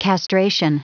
Prononciation du mot castration en anglais (fichier audio)
Prononciation du mot : castration